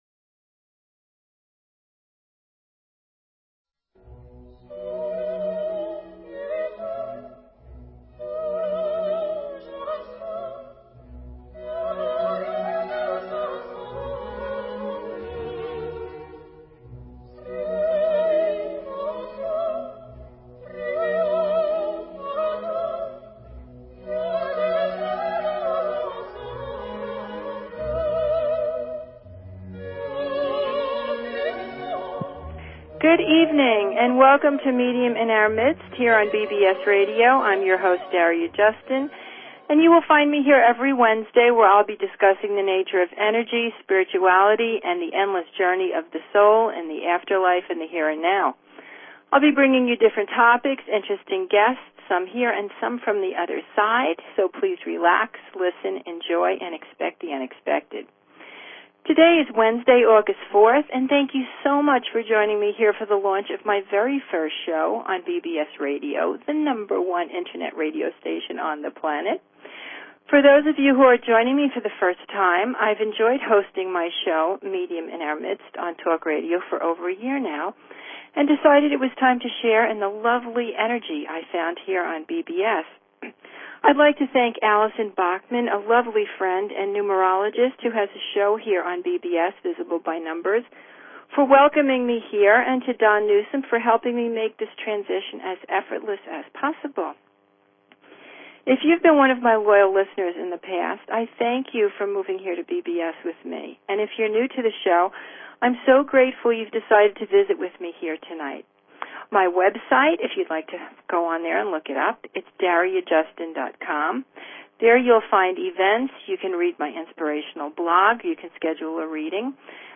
Talk Show Episode, Audio Podcast, Medium_in_our_Midst and Courtesy of BBS Radio on , show guests , about , categorized as